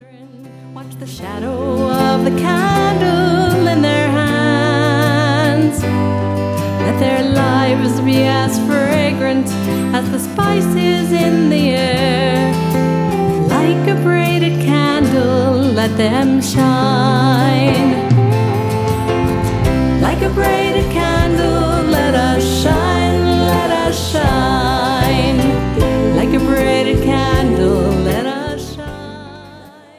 Recorded with top Israeli session players.